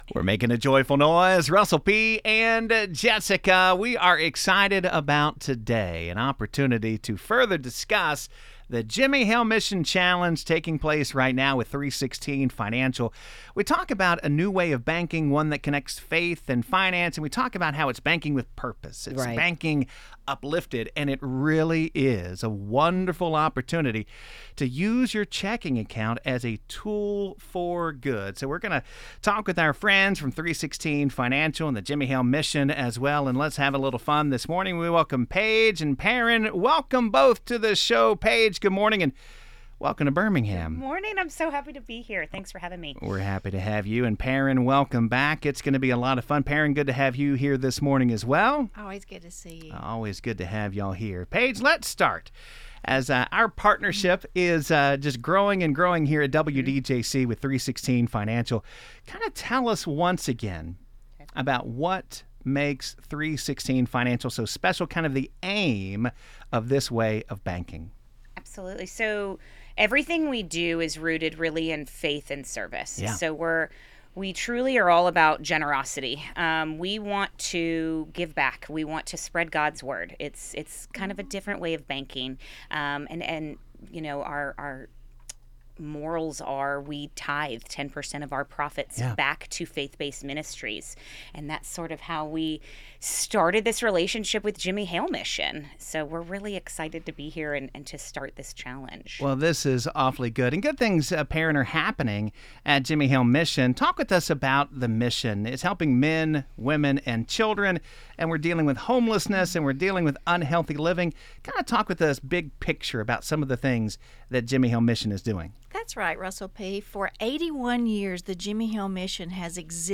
In this powerful conversation, we unpack the heart behind the Jimmie Hale Mission Challenge—a partnership that supports individuals overcoming homelessness, addiction, and crisis by connecting everyday banking to real-life impact.